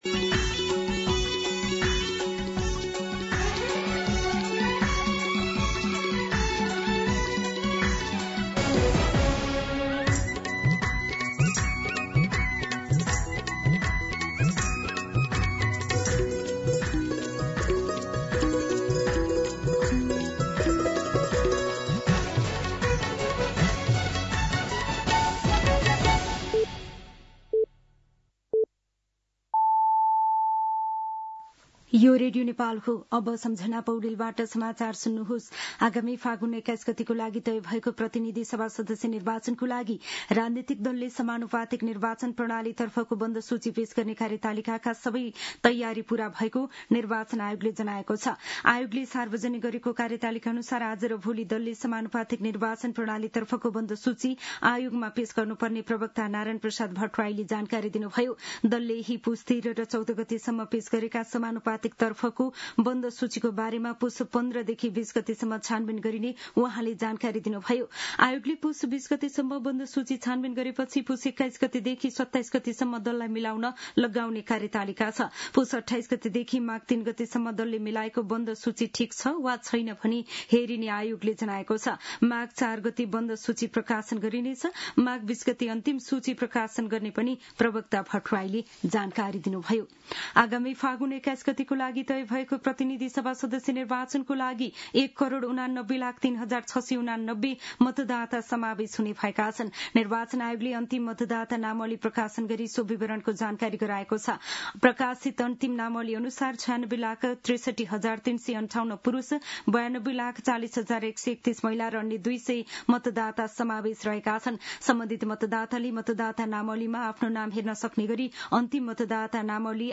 दिउँसो १ बजेको नेपाली समाचार : १३ पुष , २०८२